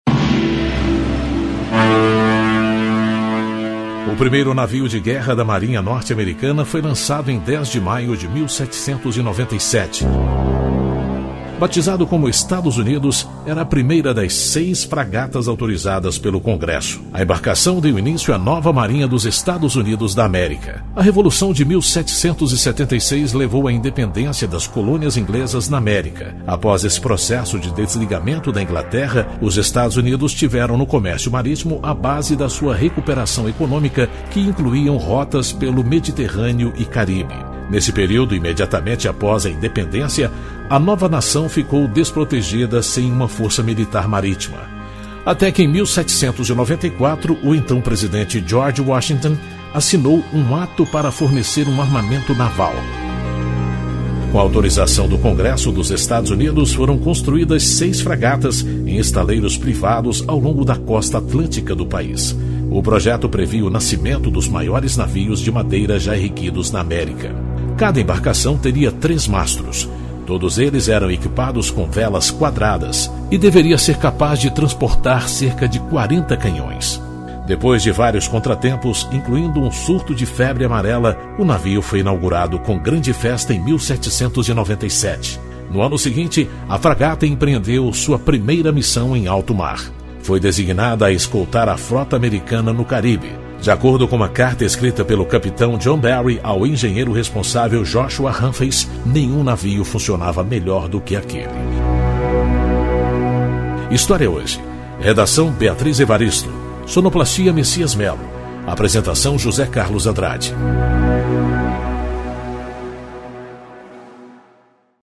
Locutor da Rede Nacional de Rádio